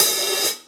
paiste hi hat6 half.wav